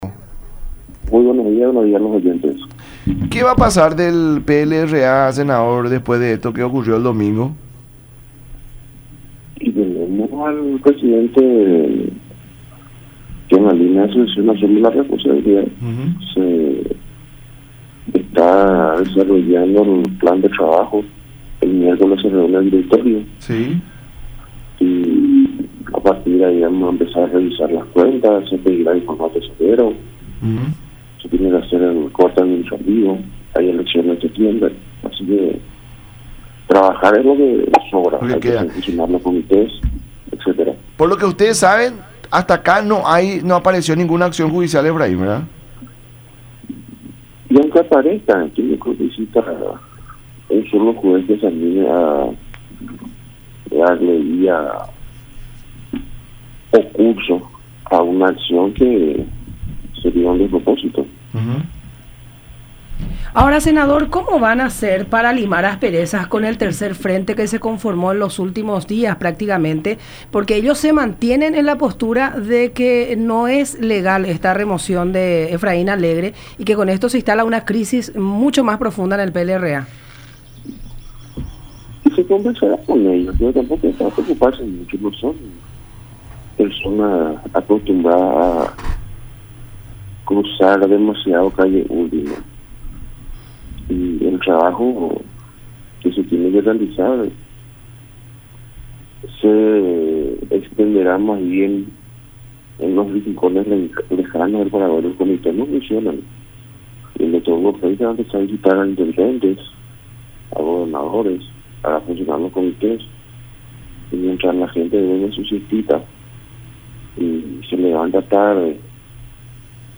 “Efraín jugó con ese grupo y tuvo 2 votos en la convención, la brecha es mayor en el interior, no tienen mucho que hacer, quieren heredar la tropa de Efraín, pero son políticos que hace poco cruzaron Calle Última como Nakayama”, declaró Dionisio Amarilla en el programa “La Mañana De Unión” por Radio La Unión y Unión Tv.